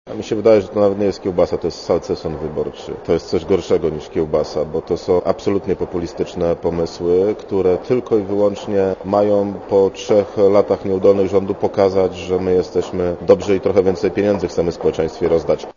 Posłuchaj komentarza Zbigniewa Chlebowskiego